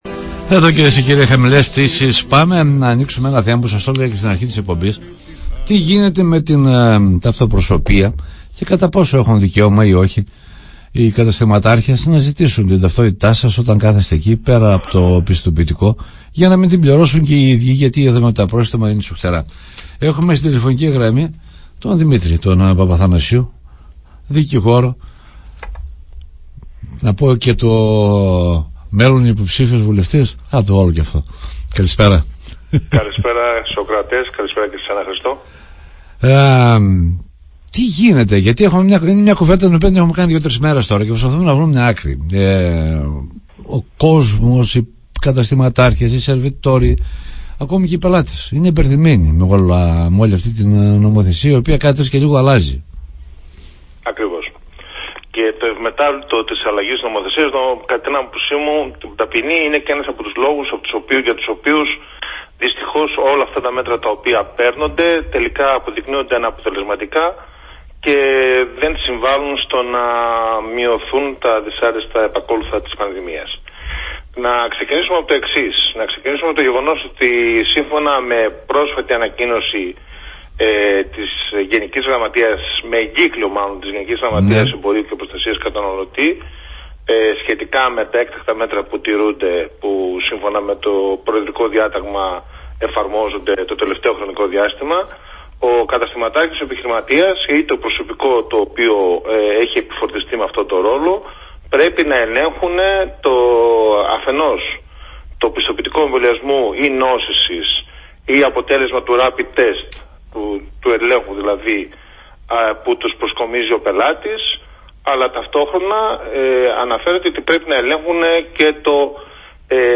στην εκπομπή “Χαμηλές Πτήσεις” στο ραδιόφωνο Ζυγός fm 100.